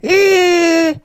mrp_get_hurt_vo_01.ogg